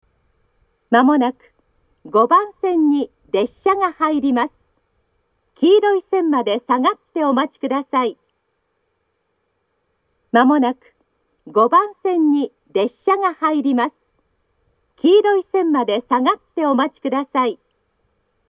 発車メロディーと接近放送があります。
５番線接近放送
aizu-wakamatsu-5bannsenn-sekkinn.mp3